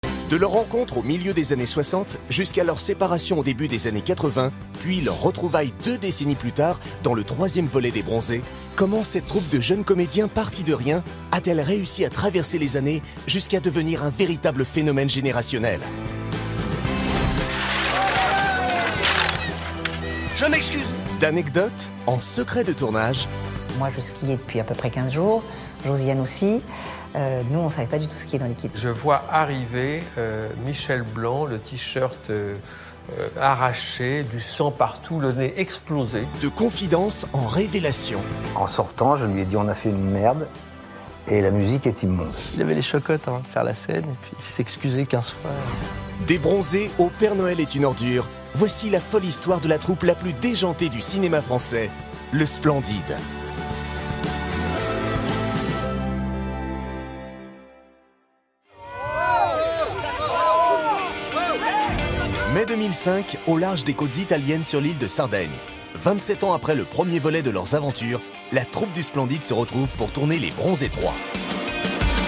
Prestation voix-off souriante et dynamique pour "De bronzés au père Noël : la folle histoire du Splendid"
Voix sympathique et souriante.
Pour la série « De bronzés au père Noël : la folle histoire du Splendid », j’ai utilisé une hauteur de voix médium pour correspondre à l’ambiance légère et humoristique de l’émission. J’ai adopté un ton souriant, sympathique et dynamique pour renforcer le caractère divertissant et convivial du contenu.